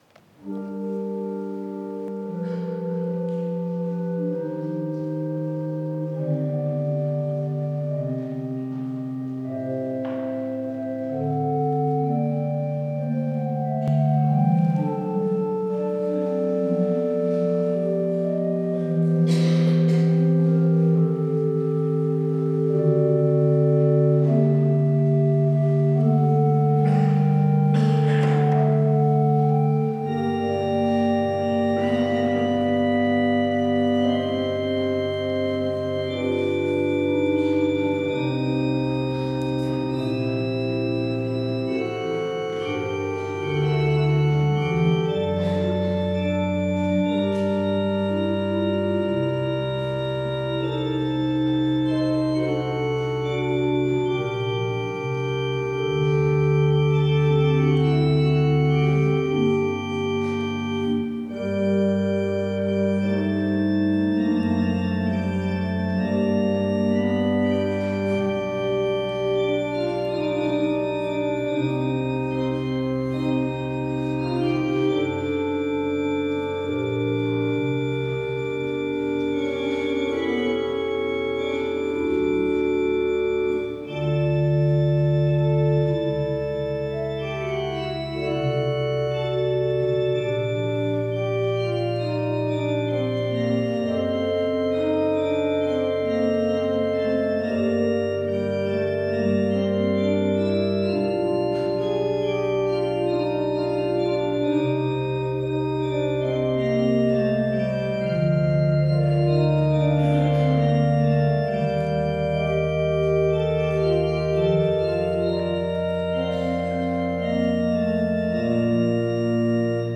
Orgelstück zum Ausgang
Audiomitschnitt unseres Gottesdienstes zum Epipaniasfest 2026.